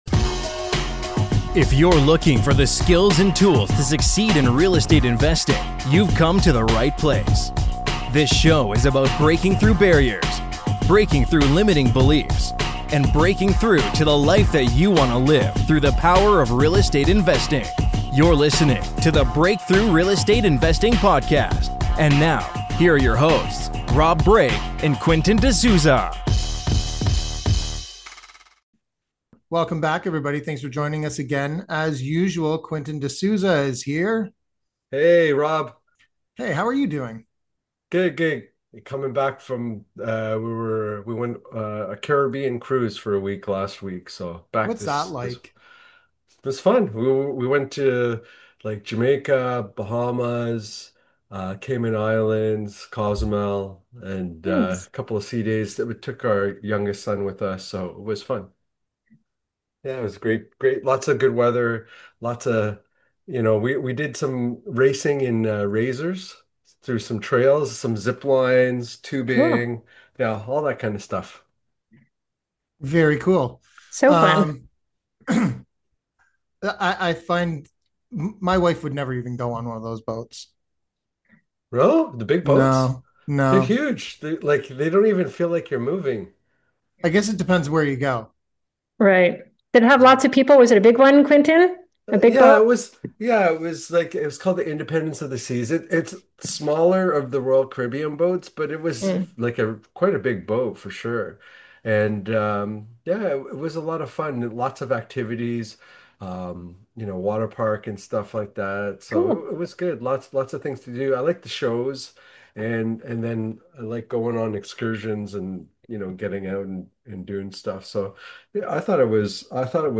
Here's what you'll learn in our interview